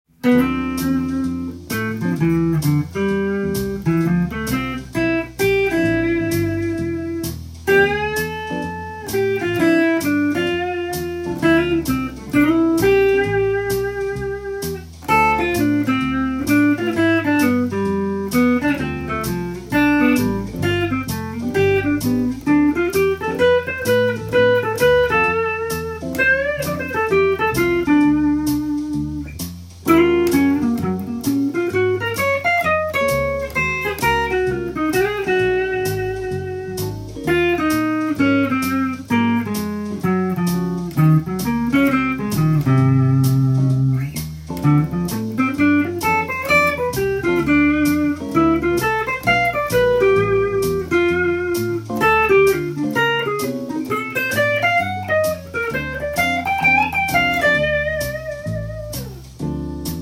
譜面通り弾いてみました
ＦＭ７のコードトーンやＦリディアンスケールを使いました。
わざと気持ち悪く弾いています。
ジャズの雰囲気を醸し出しながらソロを縦横無尽に弾くことが出来ます。